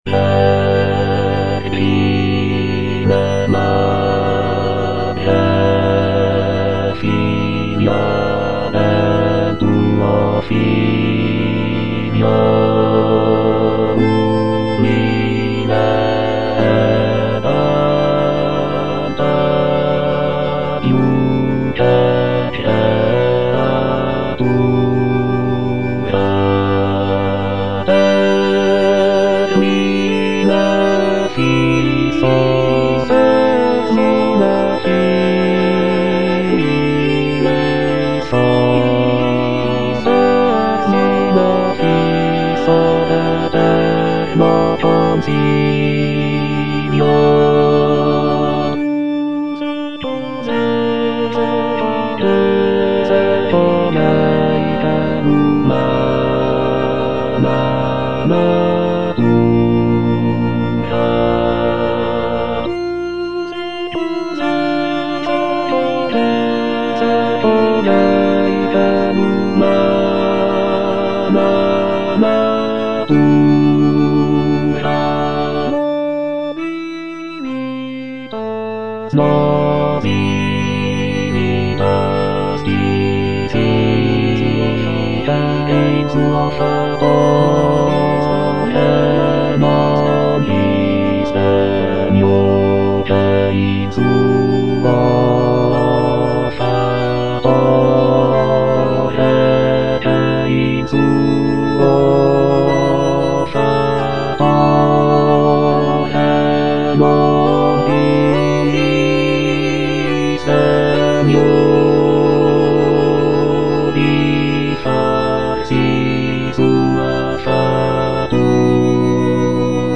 Bass (Emphasised voice and other voices)
choral work